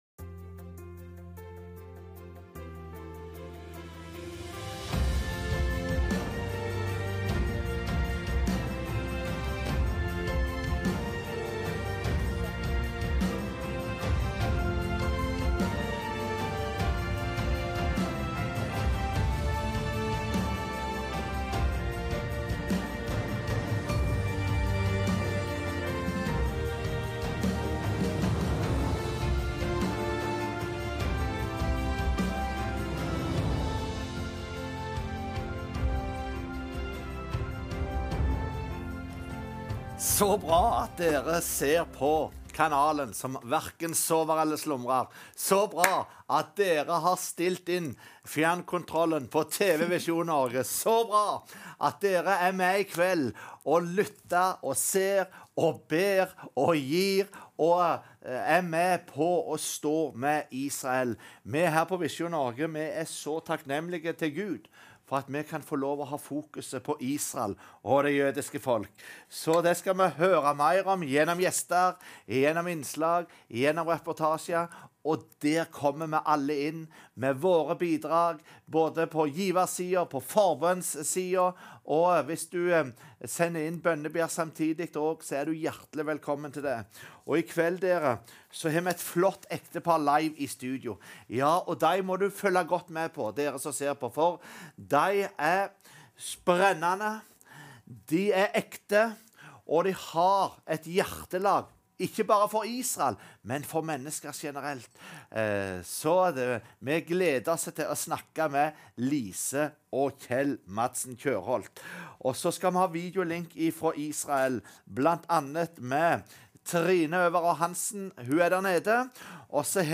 Bli med på en tankevekkende episode av Studio Direkte, hvor vi utforsker betydningen av å støtte Israel fra et kristent perspektiv. Programmet byr på samtaler om tro, politiske forhold, og det jødiske folkets utfordringer. Vi møter engasjerte gjester som deler sine erfaringer og synspunkter om viktigheten av å stå med Israel i dagens verden.